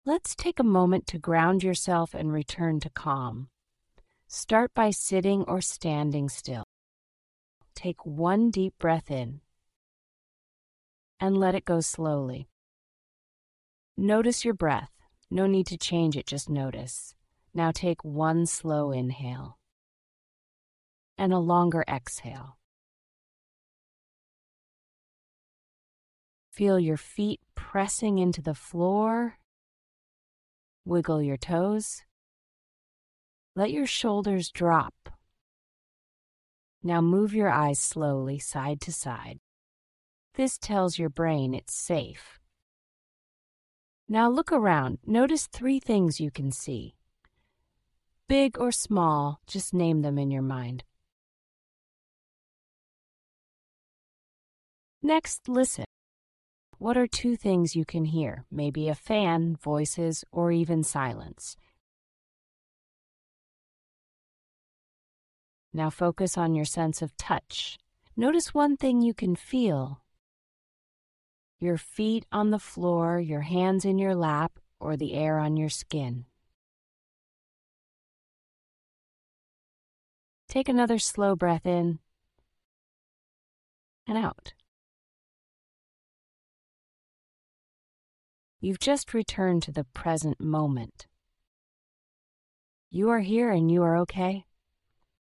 This audio will provide a guided sensory grounding practice that you can use in the moment when stressed.